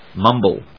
/mˈʌmbl(米国英語)/